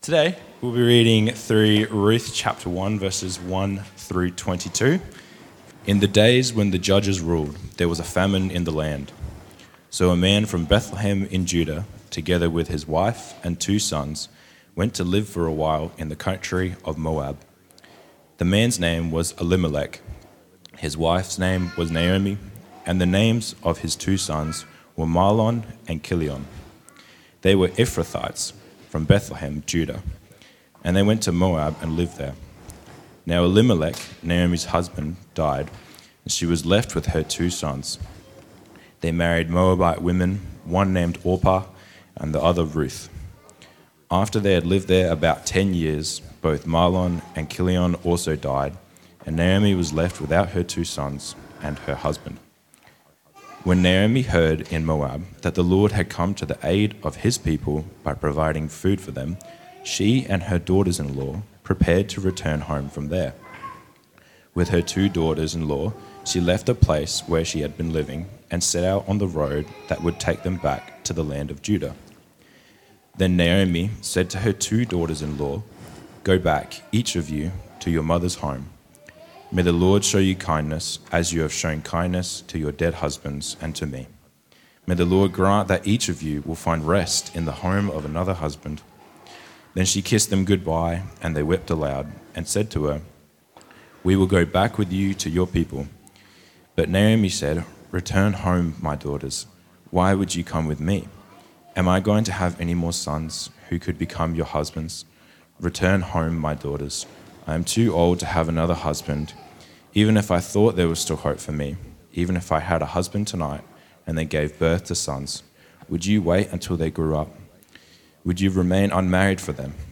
#1 The God Who Is In Control When We Hit Rock Bottom | Trinity Church Mount Barker